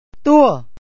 too-uh